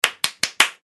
手をたたく